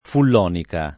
[ full 0 nika ]